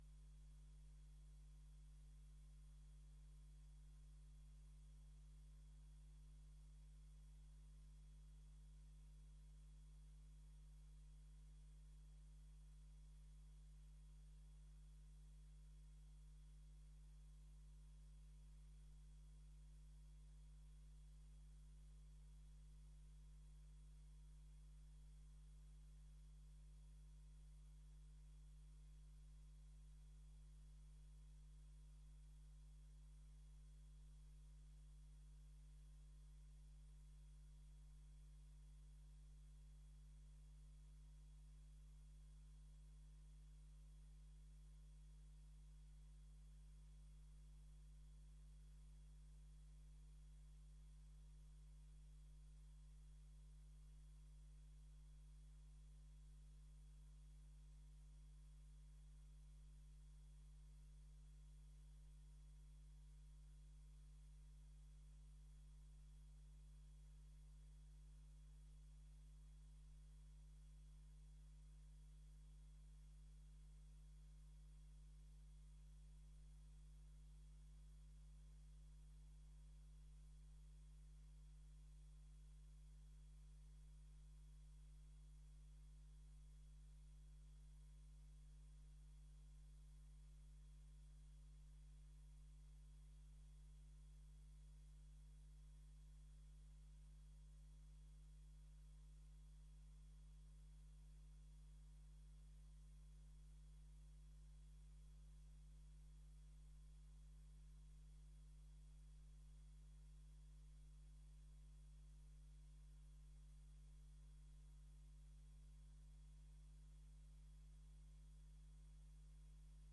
Download de volledige audio van deze vergadering
Locatie: Raadzaal Voorzitter: H.A.J. Kleine Koerkamp